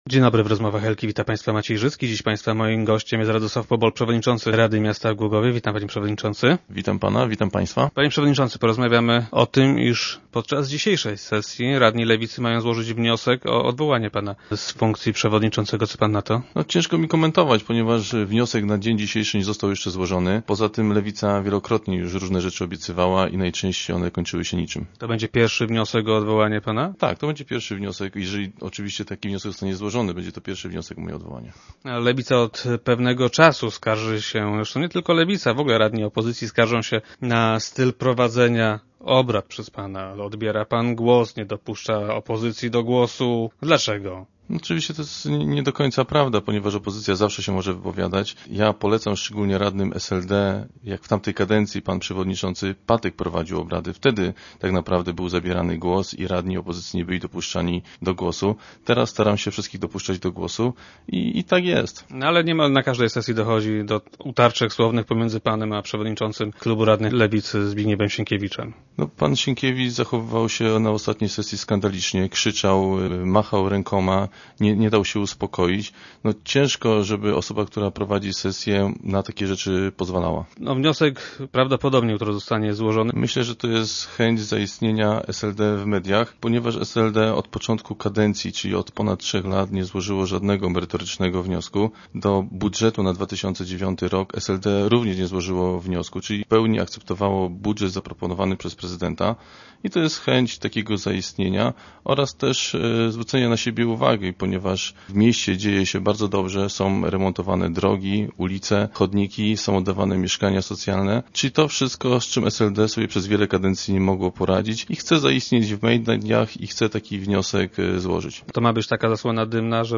Zdaniem przewodniczącego, który był dziś gościem Rozmów Elki, jest to próba odwrócenia uwago od tego, co dobrego dzieje się w mieście.
- Co tu komentować?. Wniosek jeszcze nie został złożony, a poza tym lewica wielokrotnie różne rzeczy obiecywała i najczęściej na tych obietnicach się kończyło - powiedział w radiowym studio przewodniczący Pobol.